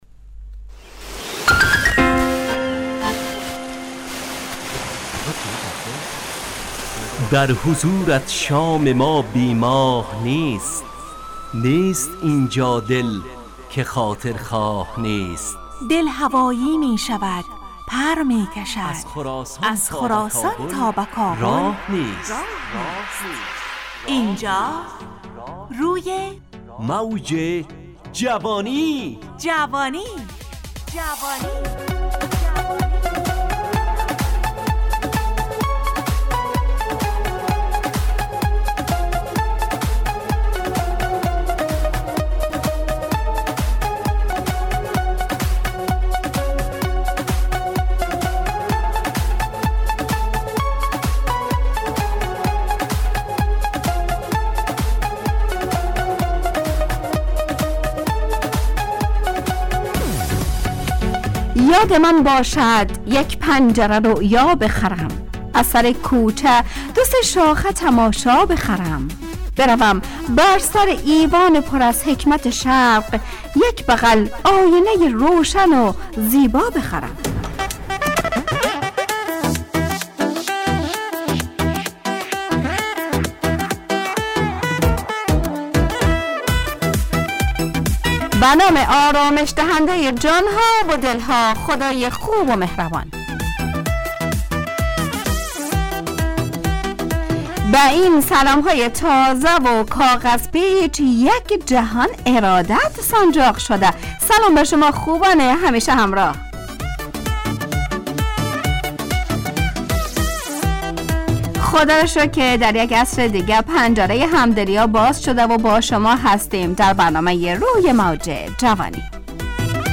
روی موج جوانی، برنامه شادو عصرانه رادیودری.
همراه با ترانه و موسیقی مدت برنامه 70 دقیقه .